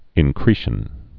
(ĭn-krēshən)